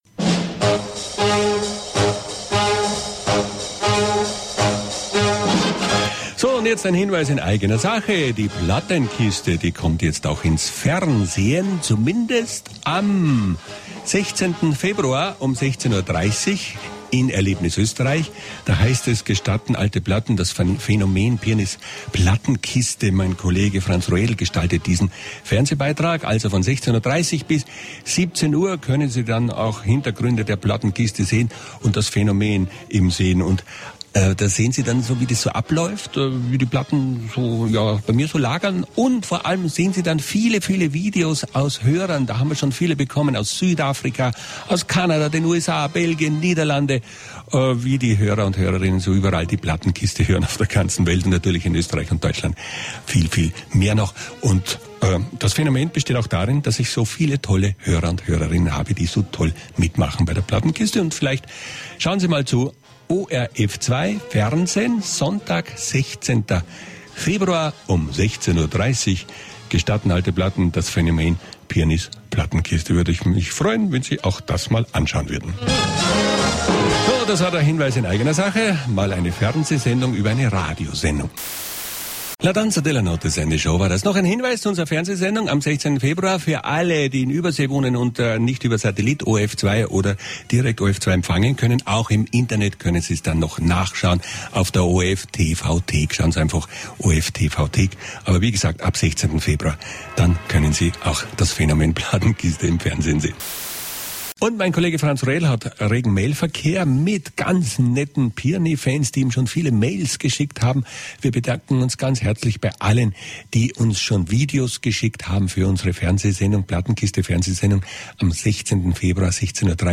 476_g Gestatten alte Platten Moderation.mp3